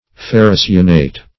Search Result for " ferrocyanate" : The Collaborative International Dictionary of English v.0.48: Ferrocyanate \Fer`ro*cy"a*nate\, n. [Ferro- + cyanate: cf. F. ferrocyanate.]